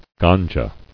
[gan·ja]